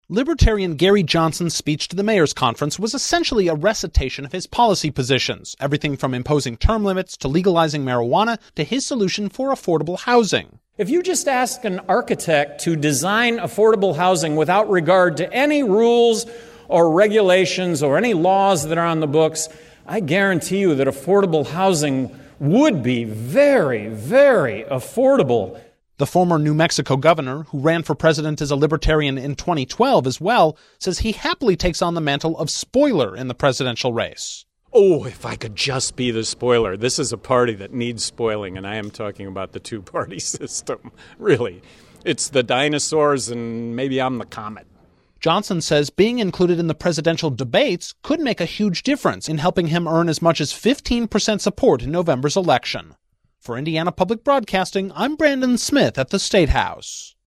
Libertarian presidential candidate addresses US mayors at Indianapolis conference
Gary-Johnson-at-Mayors-Conf.mp3